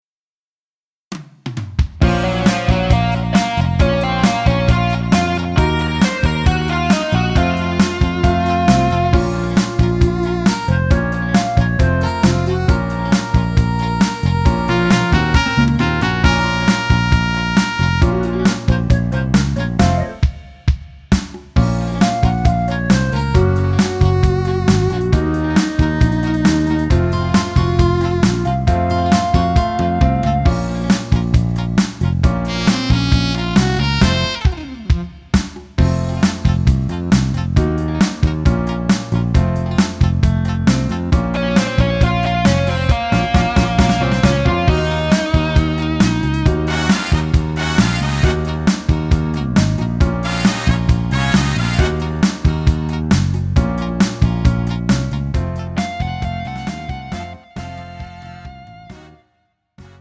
음정 여자키 2:24
장르 가요 구분 Pro MR
Pro MR은 공연, 축가, 전문 커버 등에 적합한 고음질 반주입니다.